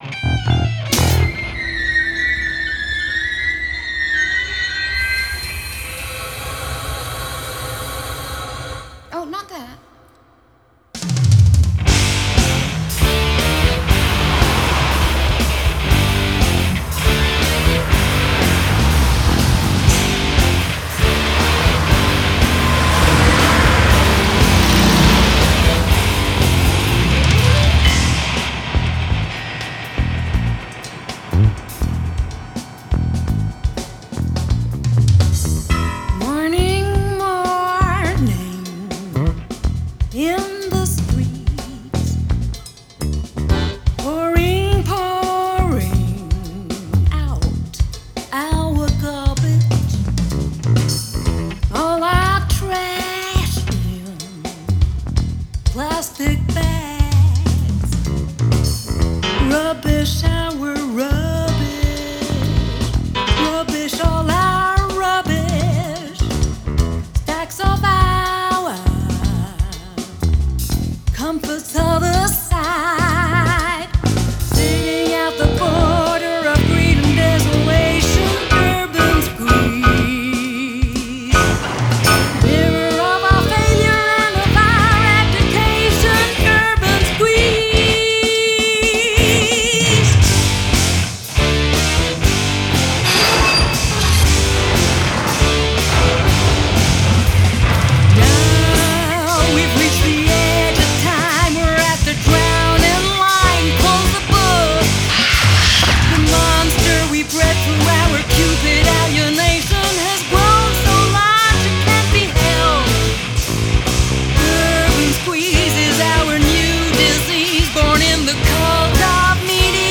voice
guitars
accordeon / piano
bass
drums
samplings
reeds